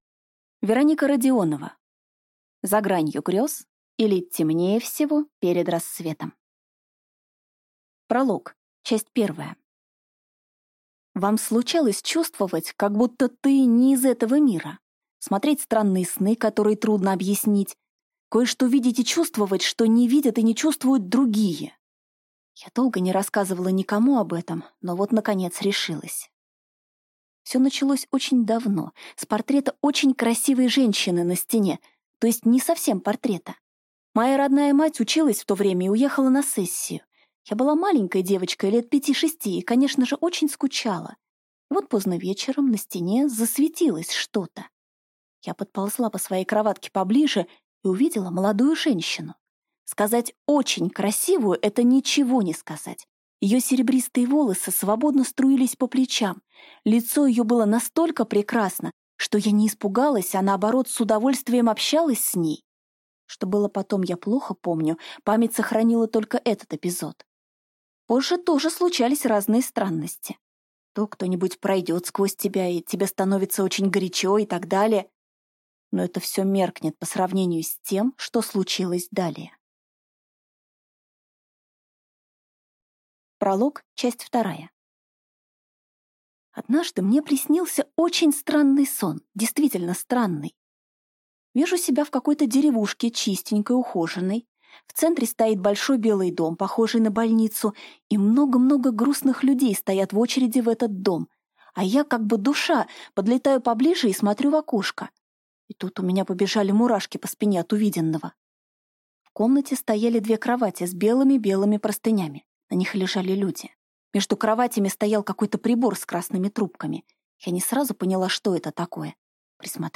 Аудиокнига За гранью грёз, или Темнее всего перед рассветом. Книга 1 | Библиотека аудиокниг